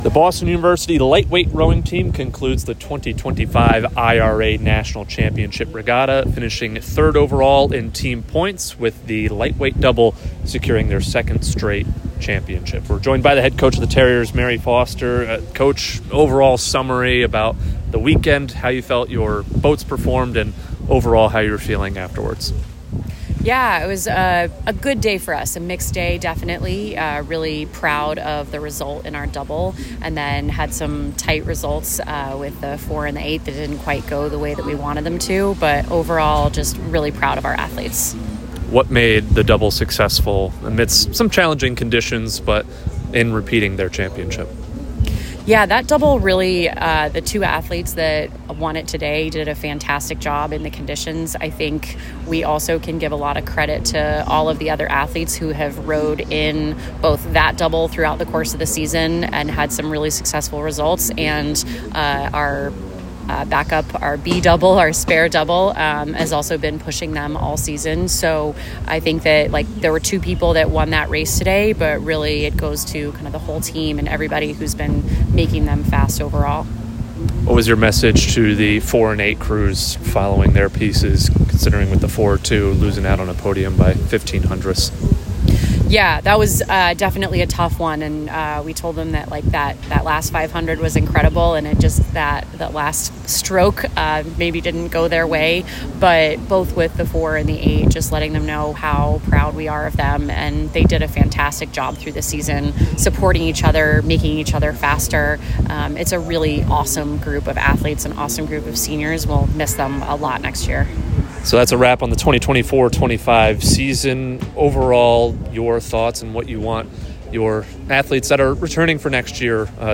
Lightweight Rowing / IRA Championship Post Regatta Interview